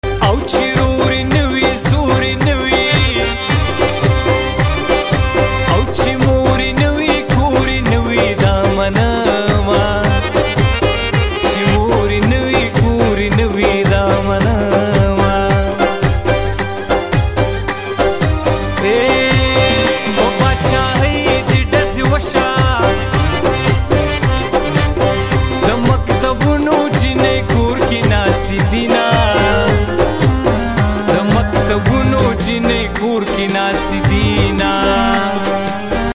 Pashto New Tappy 2025